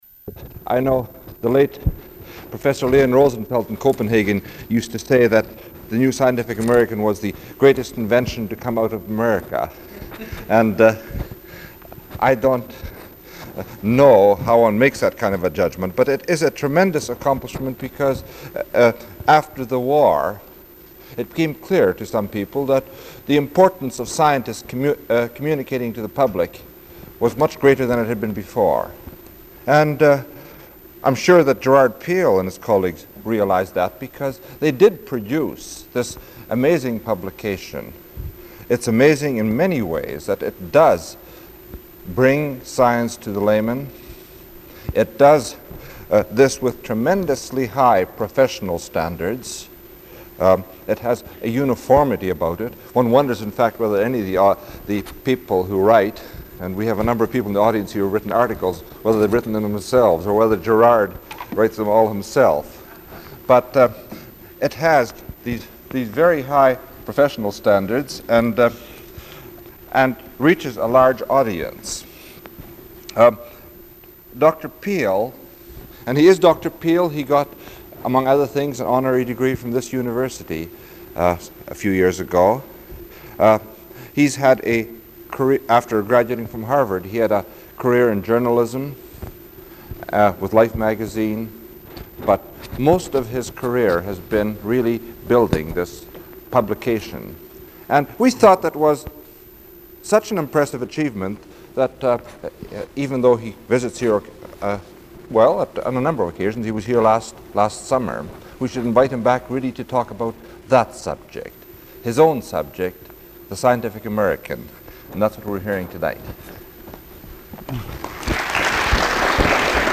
Item consists of a digitized copy of an audio recording of a Vancouver Institute lecture given by Gerard Piel on February 28, 1976.